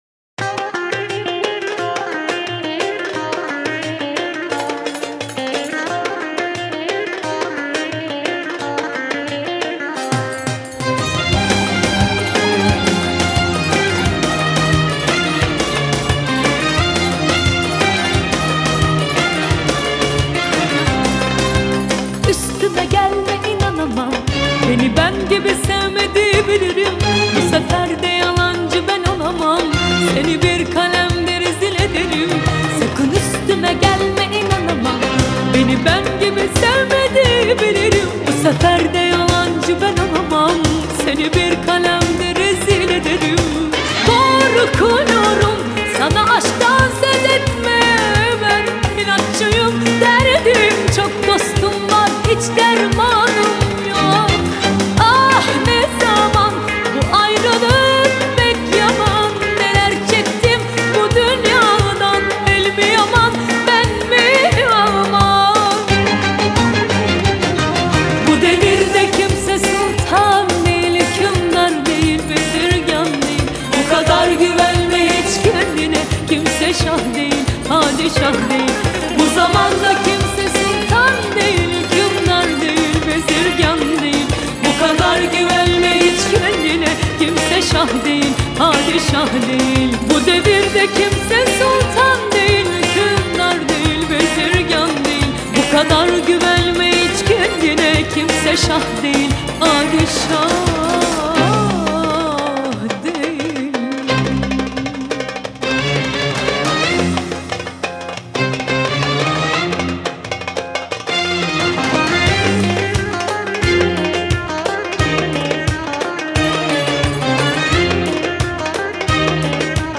آهنگ ترکیه ای